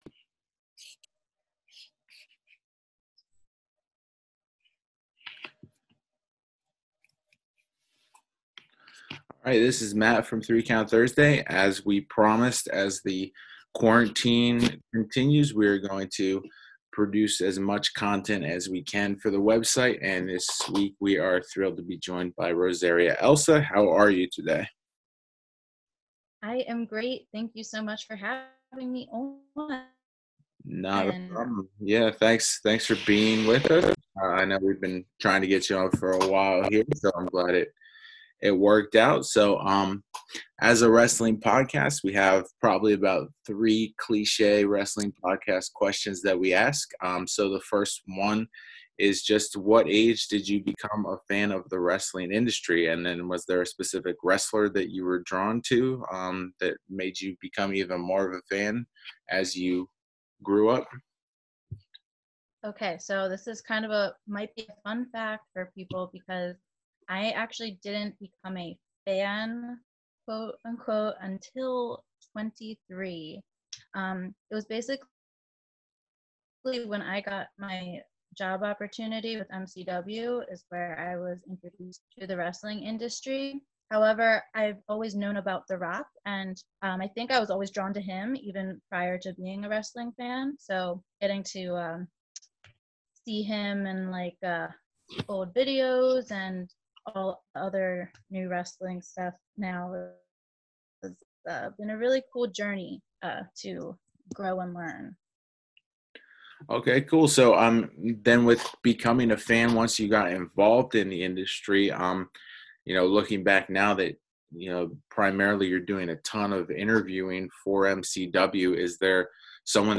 Website Exclusive Interviews